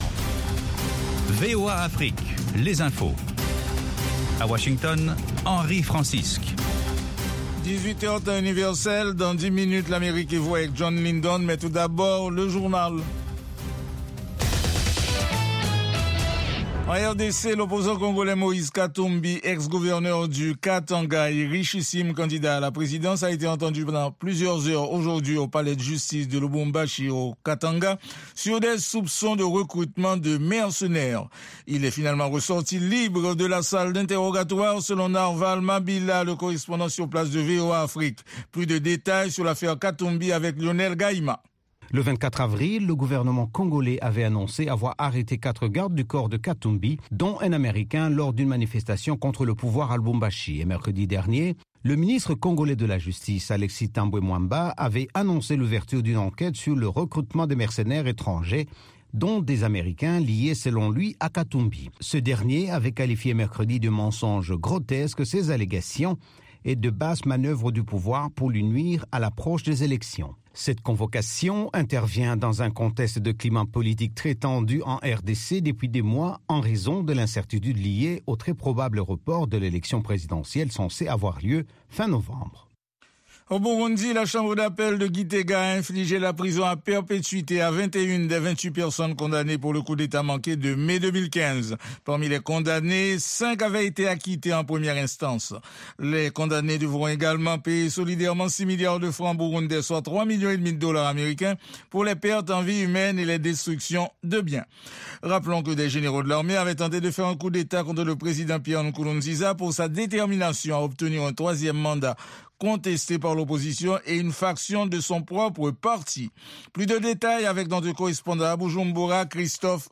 10 min Newscast